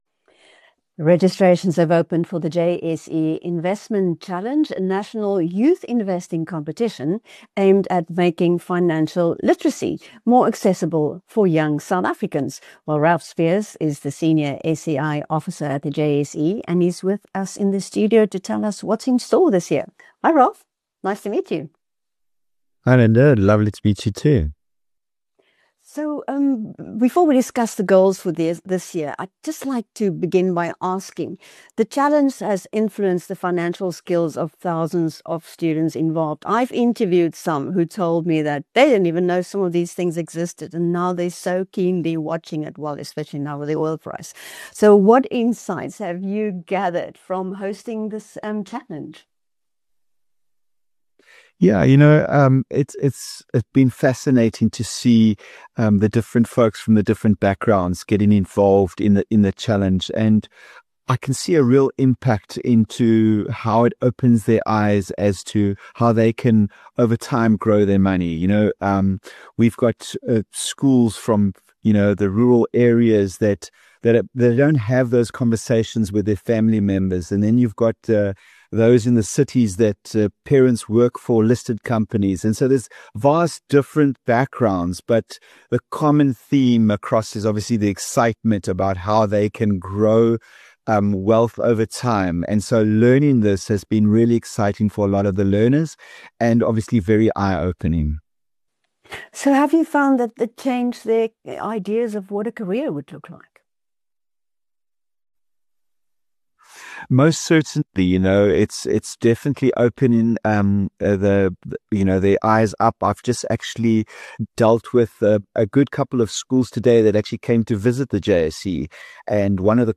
After drawing more than 65,000 pupils and students last year, the JSE Investment Challenge has set its sights on 100,000 as it looks to build a new generation that’s money and investment savvy. In an interview with BizNews